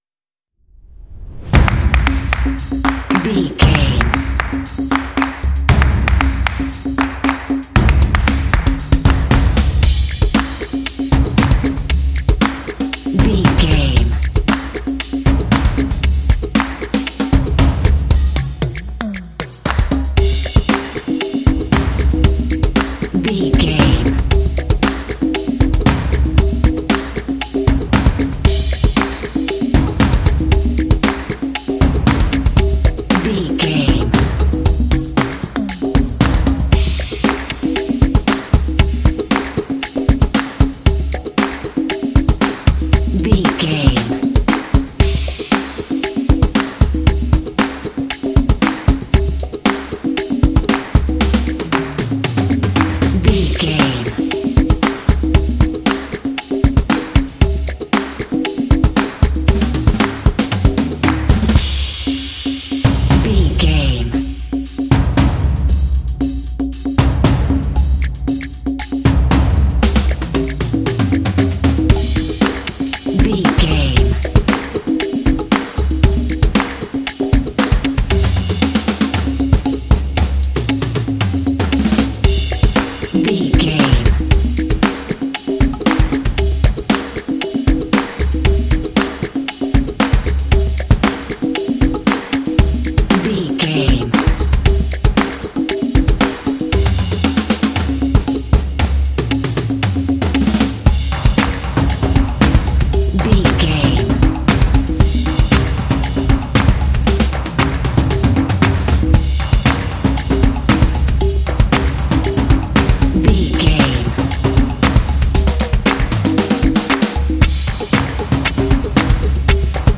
Atonal
driving
epic
drumline